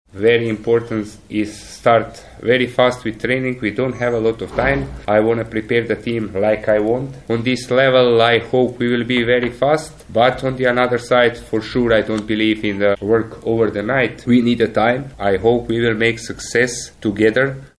Mam nadzieję, że razem osiągniemy sukces) Mówi chorwacki szkoleniowiec.